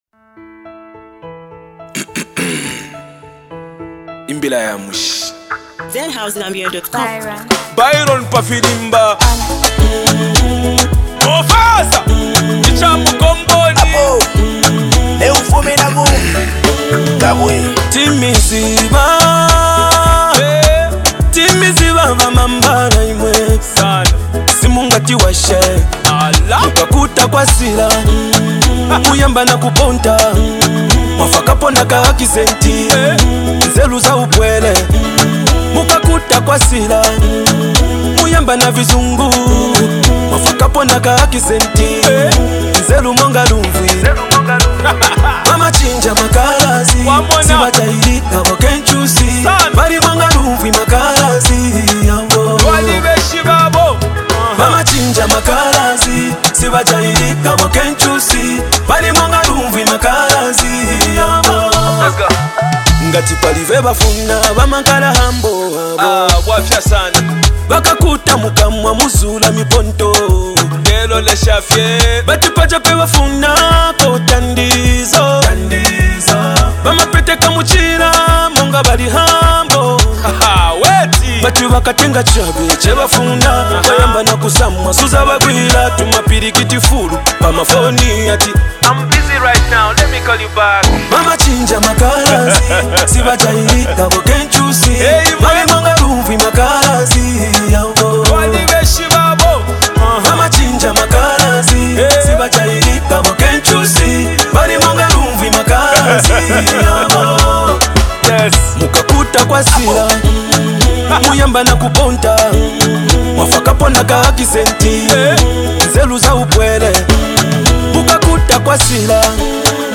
soulful vocals
A track full of emotion, melody